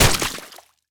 poly_gore01.wav